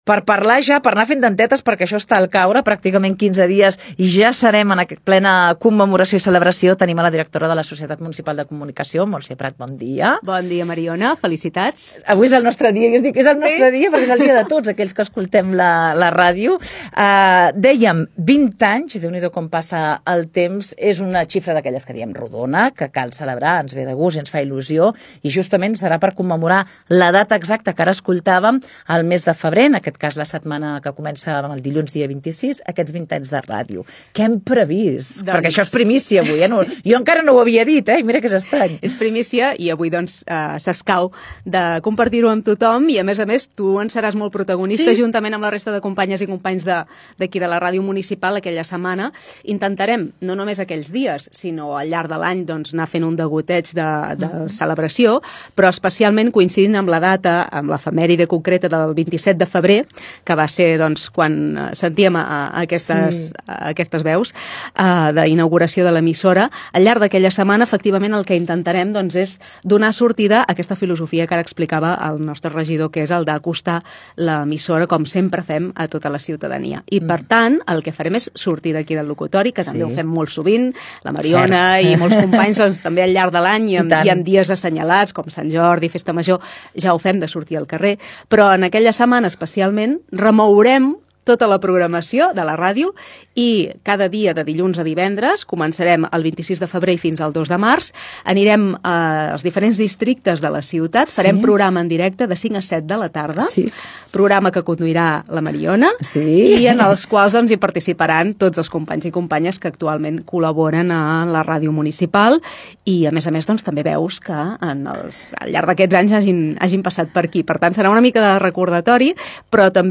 El submarí: entrevistada